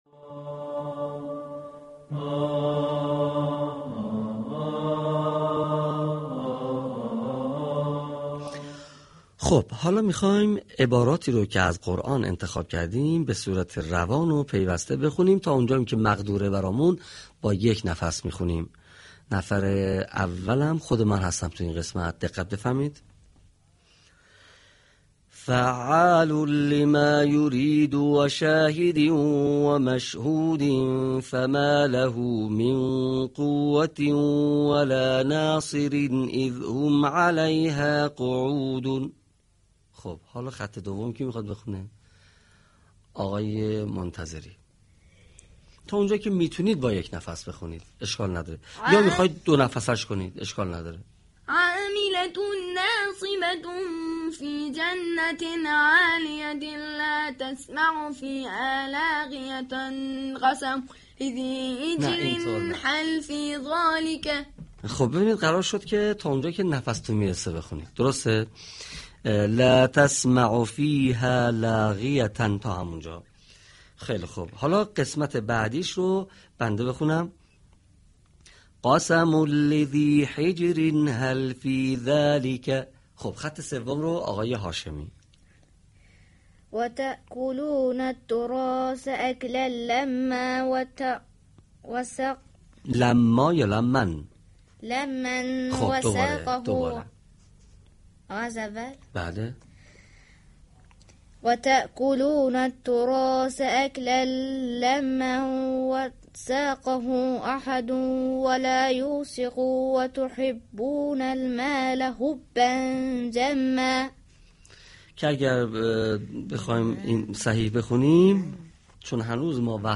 فایل صوتی/تمرین درس هشتم_تنوین